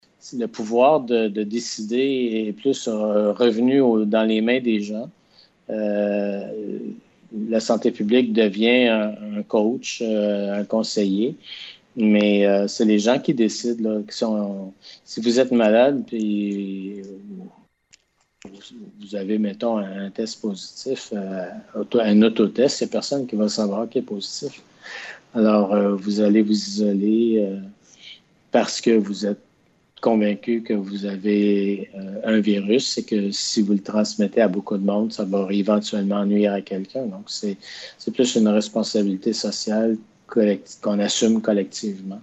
Une vingtaine de personnes ont participé à une discussion virtuelle avec le directeur de la santé publique de la Gaspésie.